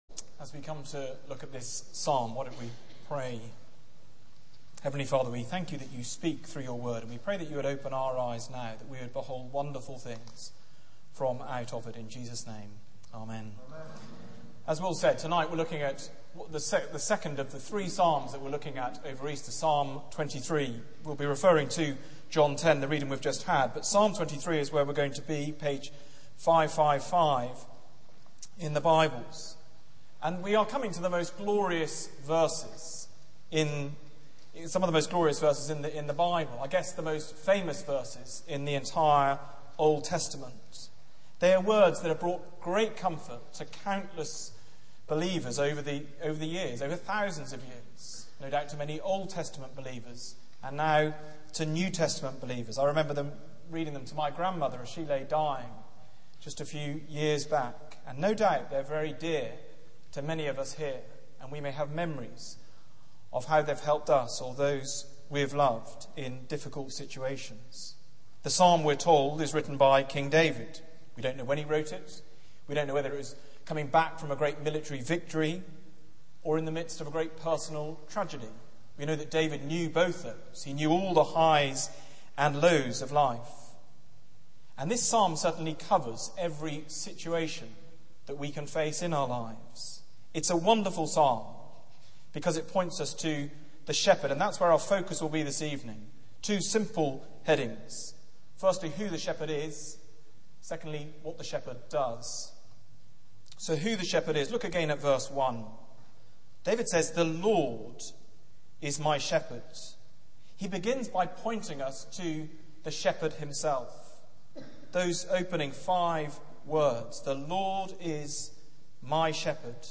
Media for 6:30pm Service on Sun 11th Apr 2010 18:30 Speaker
Easter Psalms Theme: Christ the Shepherd Sermon Search the media library There are recordings here going back several years.